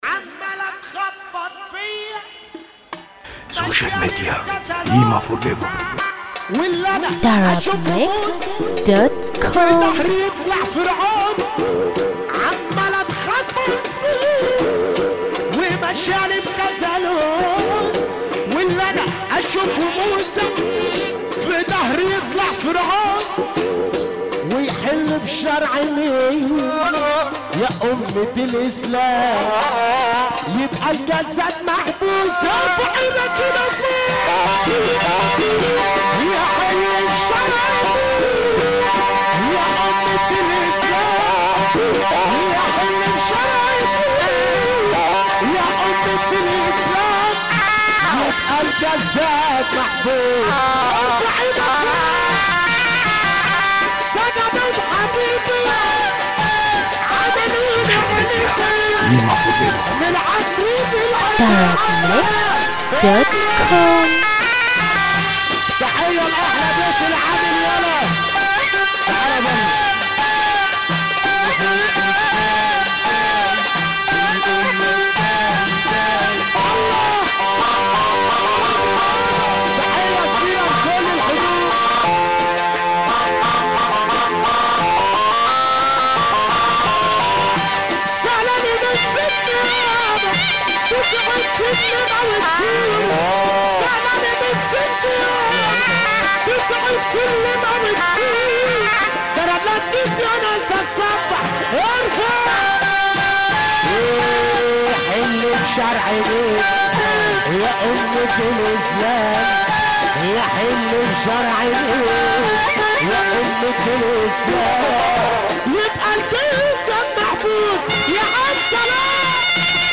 موال
بشكل حزين جدا